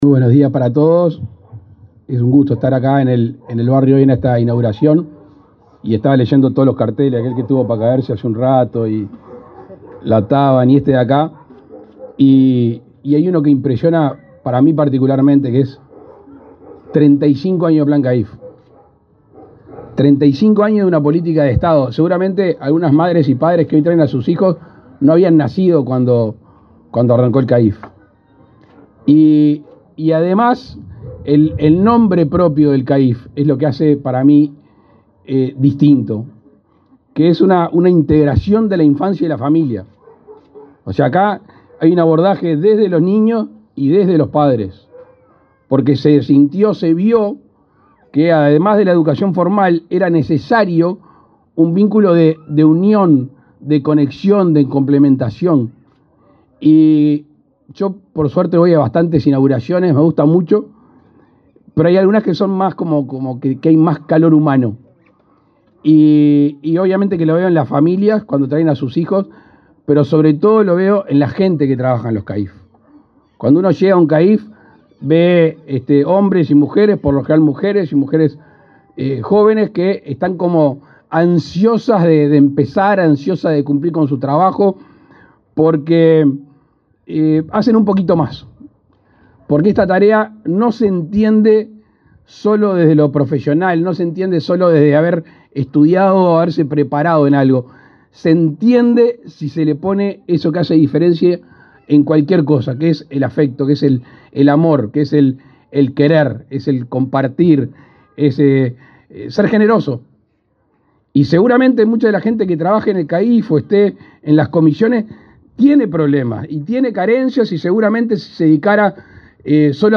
Palabras del presidente Luis Lacalle Pou
El presidente Luis Lacalle Pou encabezó, este viernes 19 en Montevideo, la inauguración de un centro de atención a la infancia y a la familia (CAIF)